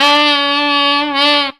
BLOWER.wav